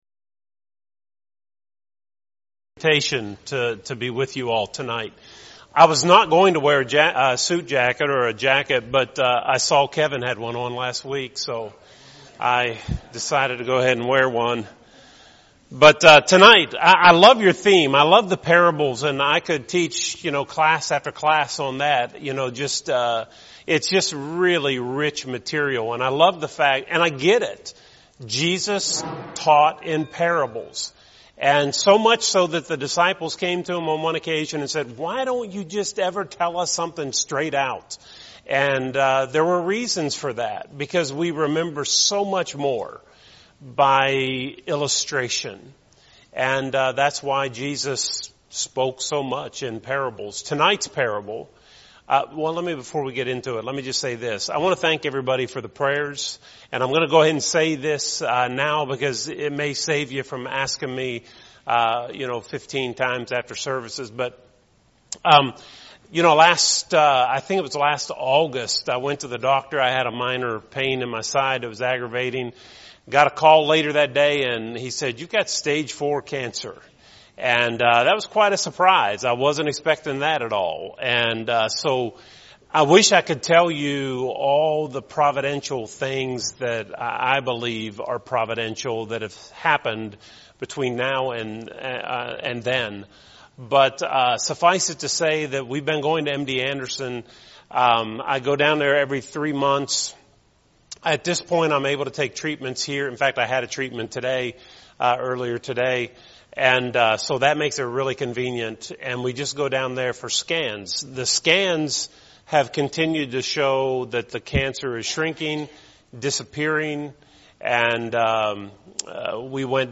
Midweek Bible Class